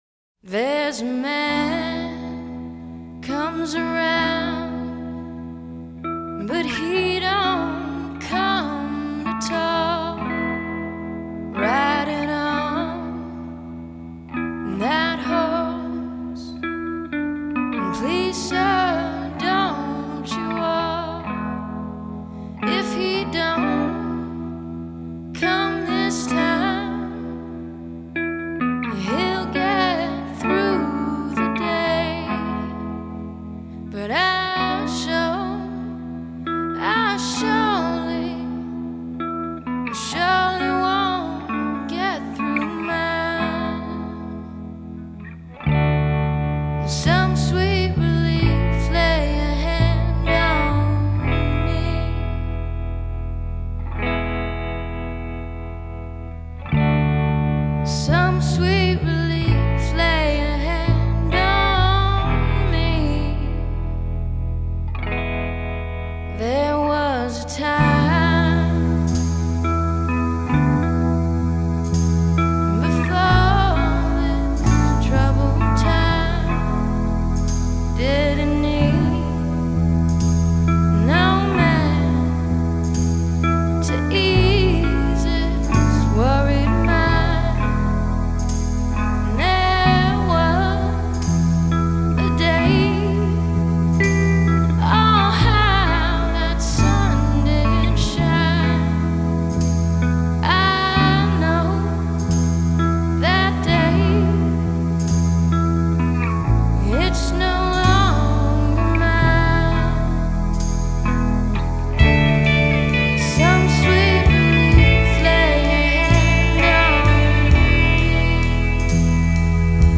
termed Ambient Soul
Vocals, Guitar, Bass
Electric Piano, Organ
Percussion